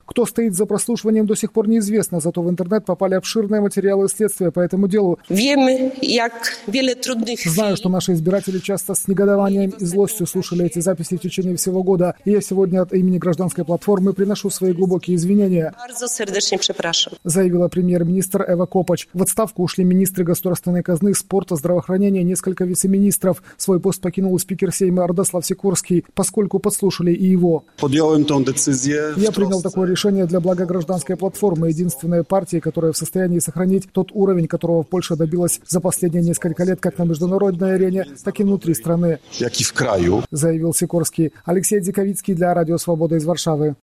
Рассказывает корреспондент Радио Свобода